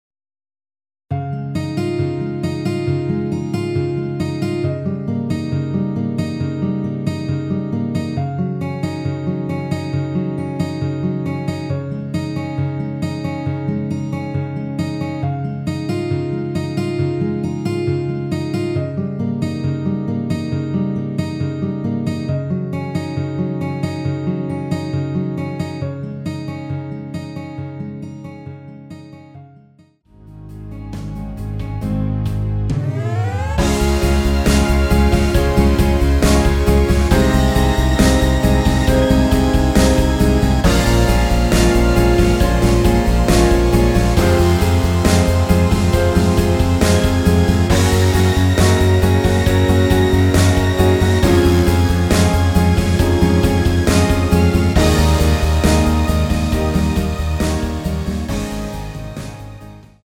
Db
앞부분30초, 뒷부분30초씩 편집해서 올려 드리고 있습니다.
중간에 음이 끈어지고 다시 나오는 이유는
곡명 옆 (-1)은 반음 내림, (+1)은 반음 올림 입니다.